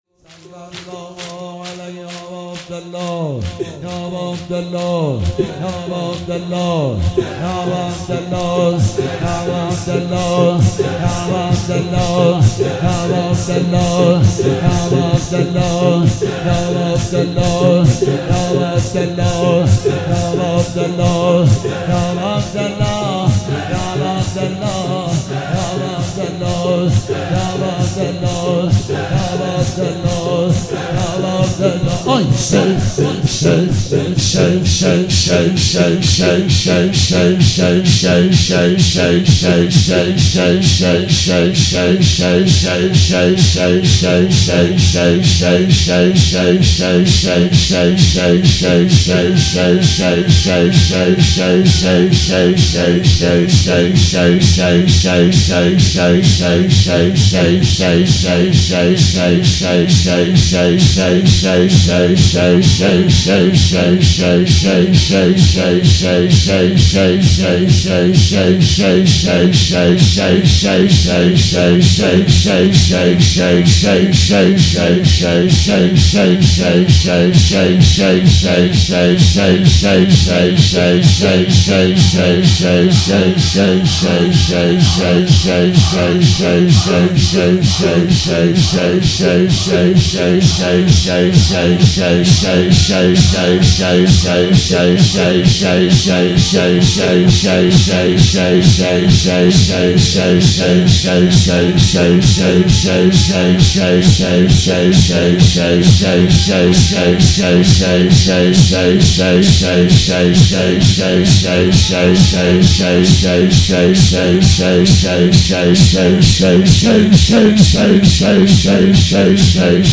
مداحی ذکر